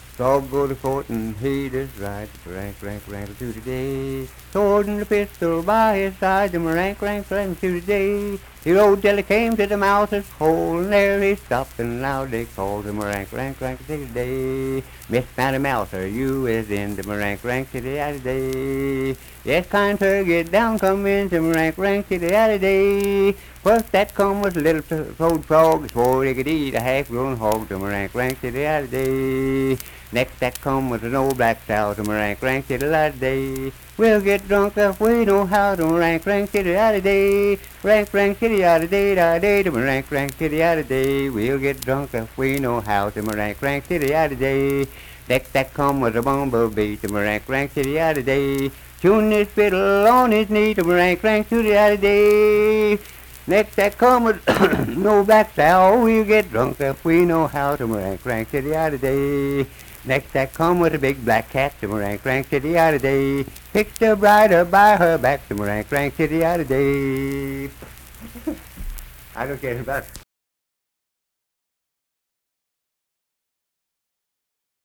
Unaccompanied vocal and banjo music
Children's Songs, Dance, Game, and Party Songs
Voice (sung)
Clay (W. Va.), Clay County (W. Va.)